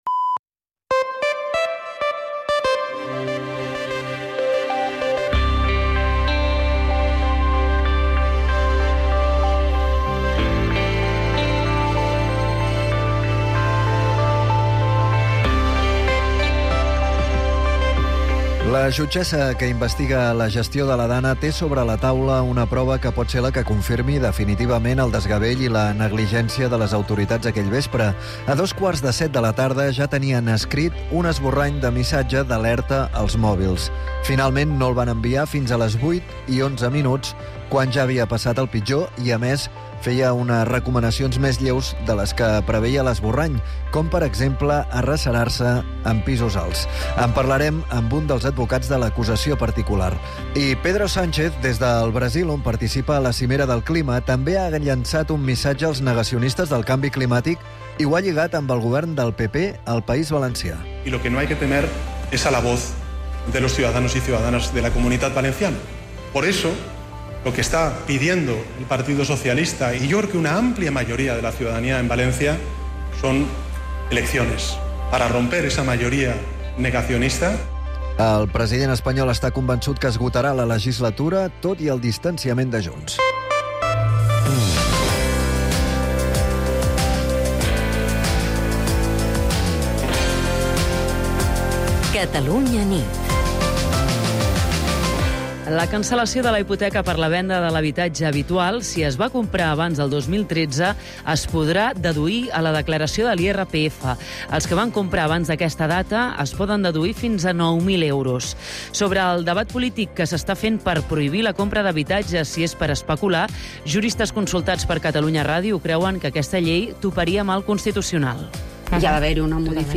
El compromís d’explicar tot el que passa i, sobretot, per què passa és la principal divisa del “Catalunya nit”, l’informatiu nocturn de Catalunya Ràdio, dirigit per Manel Alías i Agnès Marquès.